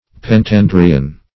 Search Result for " pentandrian" : The Collaborative International Dictionary of English v.0.48: Pentandrian \Pen*tan"dri*an\, Pentandrous \Pen*tan"drous\, a. (Bot.) Of or pertaining to the class Pentadria; having five stamens.